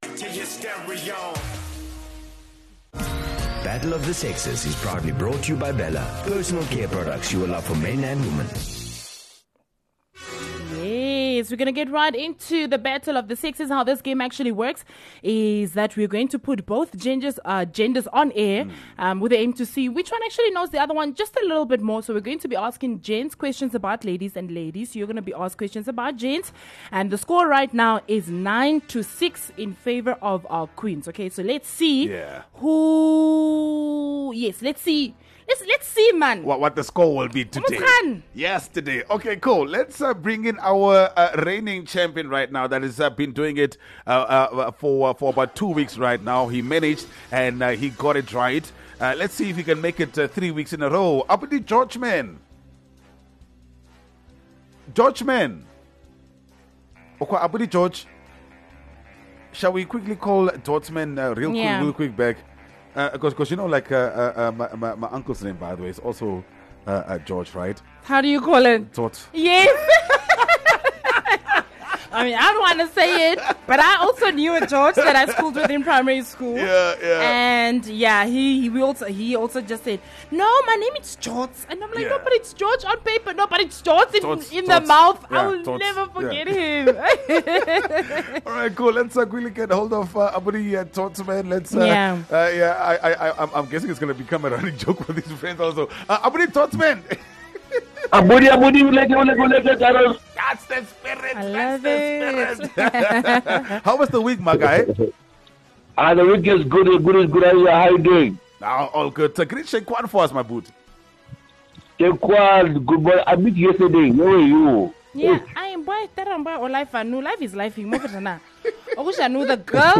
Battle of the Sexes is probably the most dramatic game show on Namibian radio. With this gameshow, we have both genders on air with the aim to see which knows more about the other. So we ask the gents questions about the ladies and ladies…we ask questions about the gents!